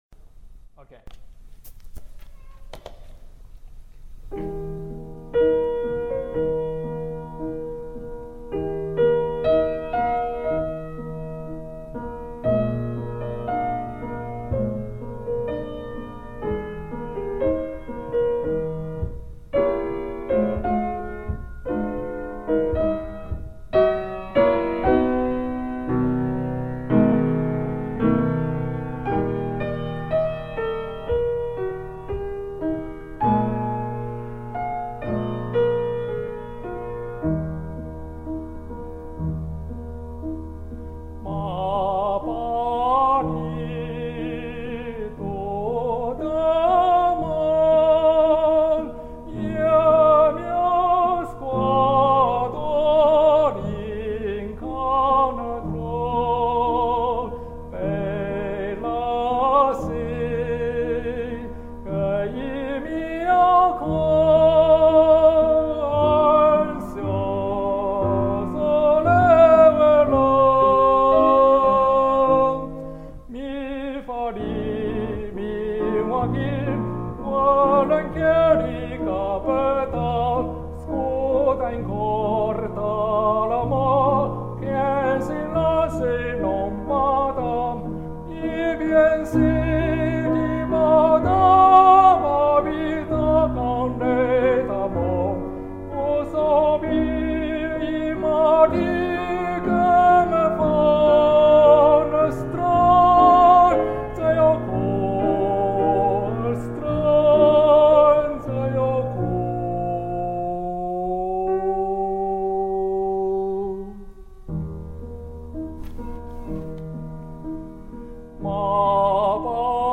现场录音